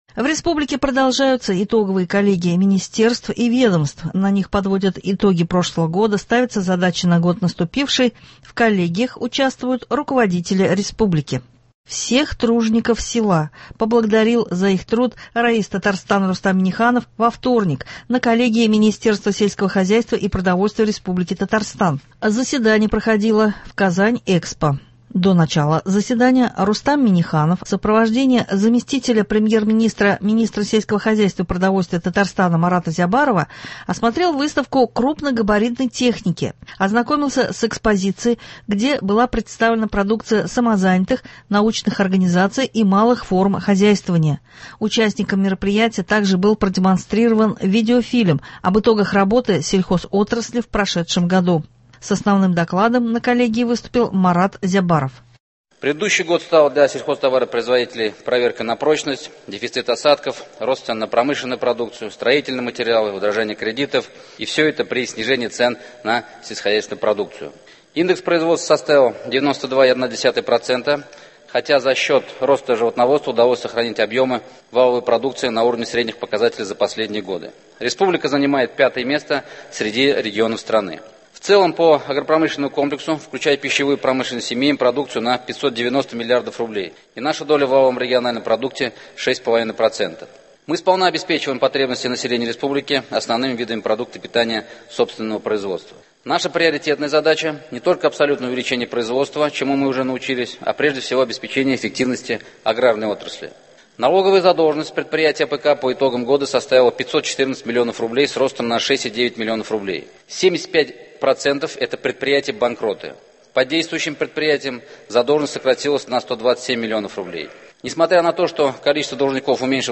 Репортаж с итоговой коллегии Минсельхозпрода Татарстана.